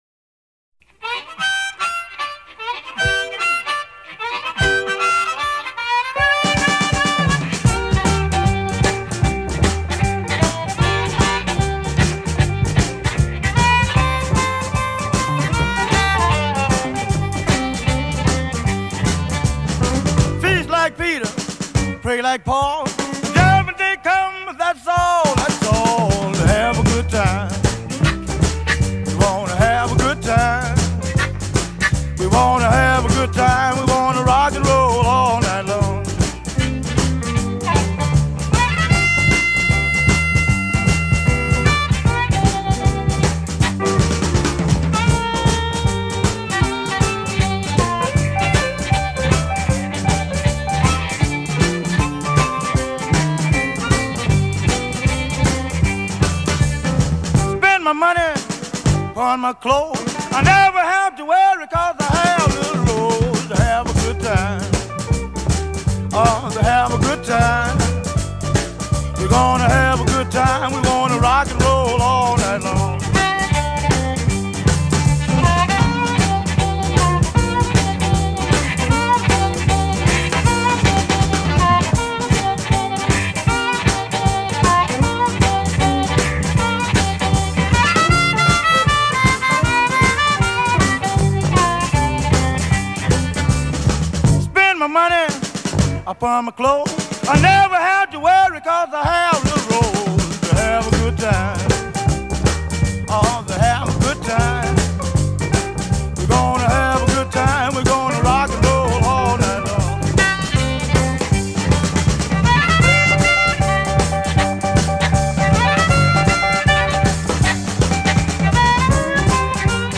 G调曲目，使用C调口琴二把位演奏